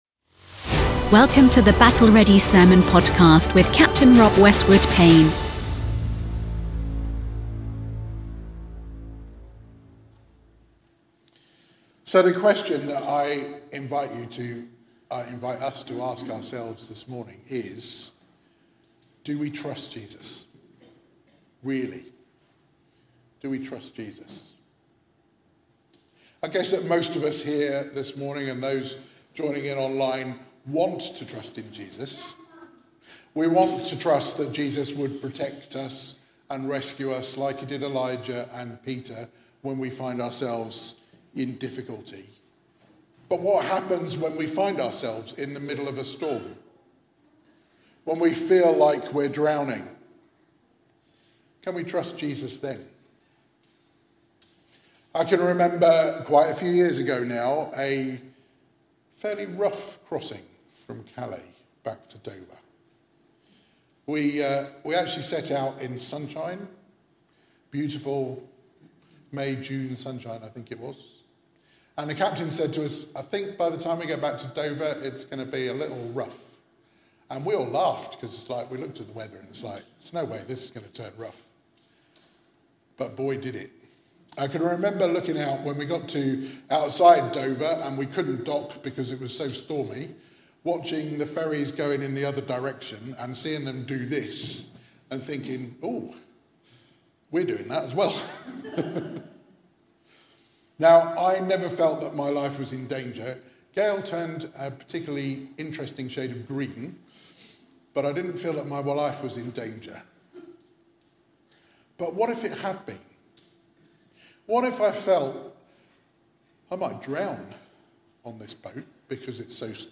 Battle Ready Sermons I'm Suddenly Drowning - Should I Trust You Jesus , Really?